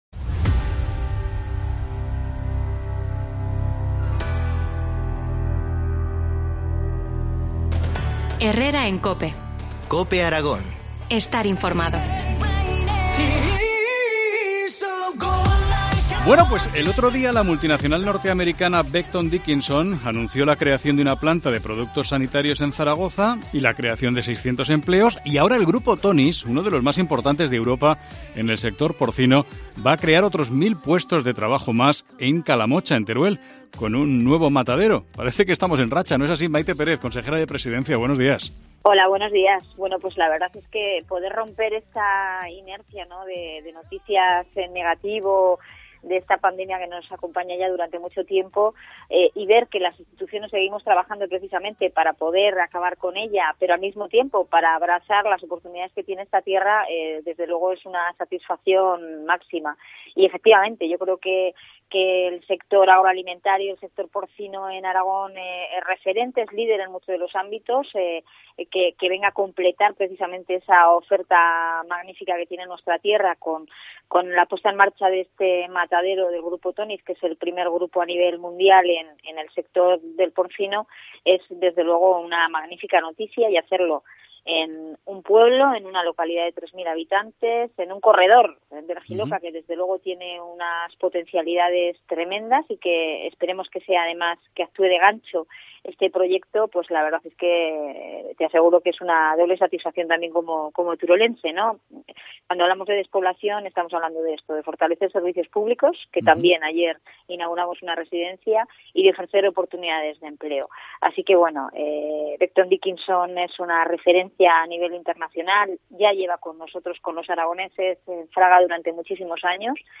Entrevista a Mayte Pérez, consejera de Presidencia del Gobierno de Aragón.